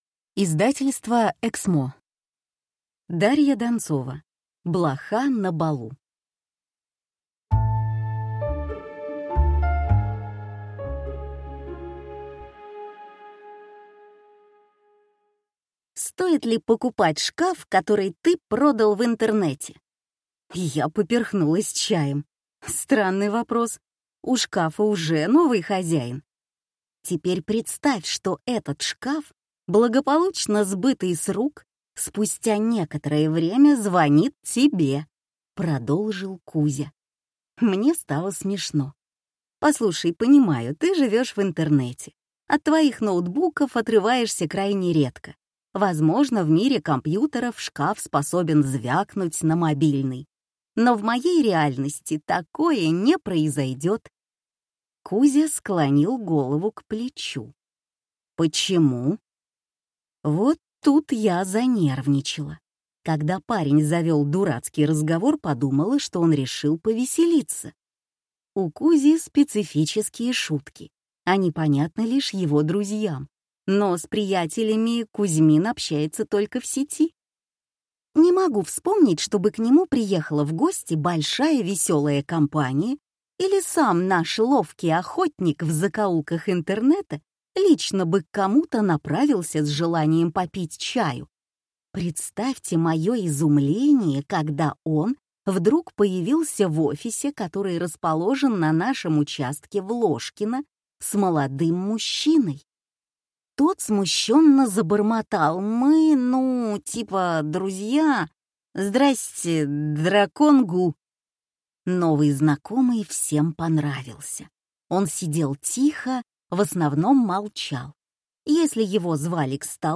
Пустые калории. Почему мы едим то, что не является едой, и при этом не можем остановиться (слушать аудиокнигу бесплатно) - автор Крис Ван Туллекен